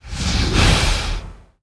Index of /App/sound/monster/skeleton_magician
attack_2_eff.wav